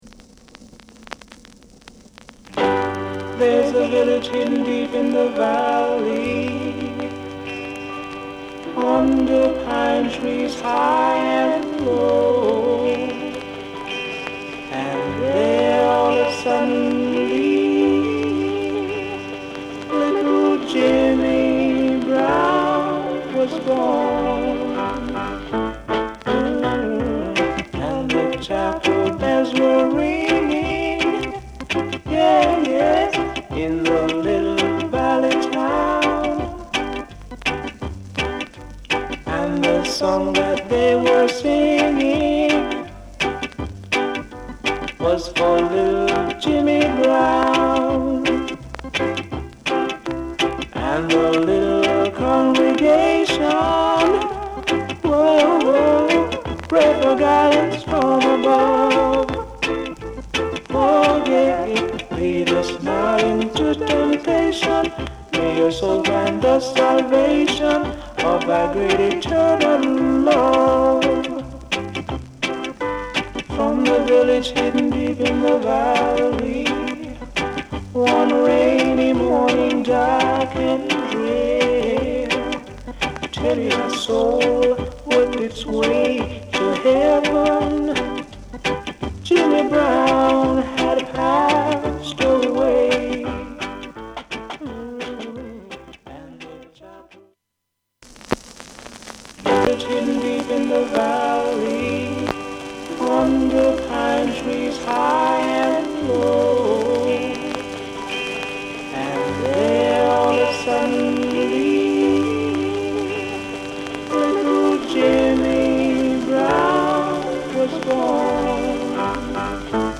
Genre: Early Reggae